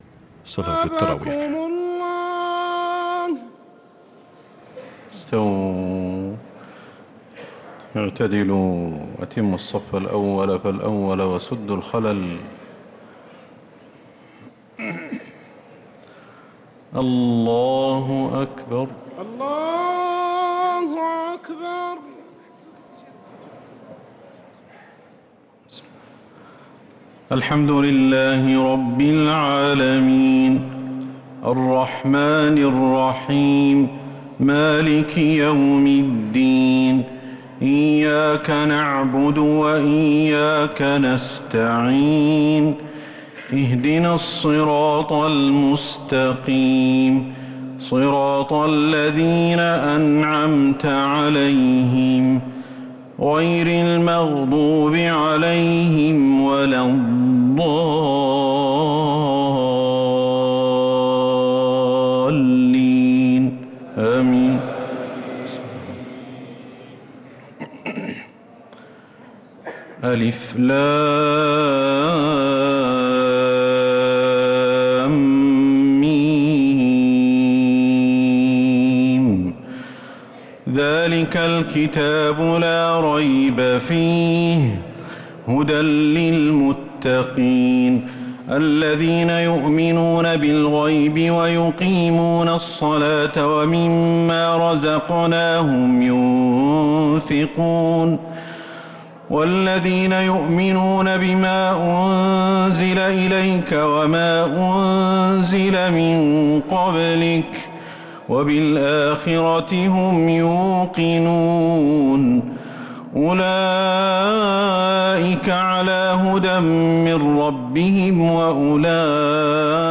تراويح ليلة 1 رمضان 1446هـ من سورة البقرة (1-66) | Taraweeh 1st night Ramadan 1446H > تراويح الحرم النبوي عام 1446 🕌 > التراويح - تلاوات الحرمين